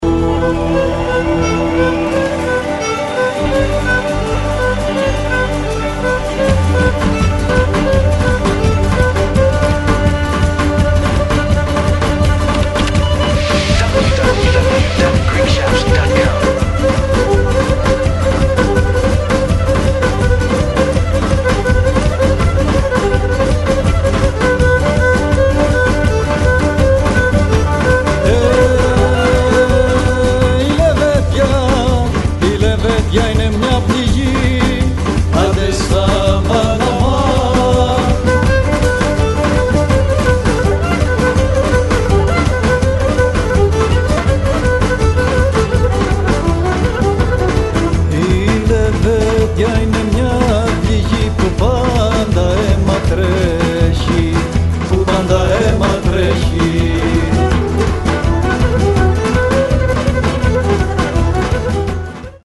A fantastic 2-CD live album recorded at the Theatro Vrahon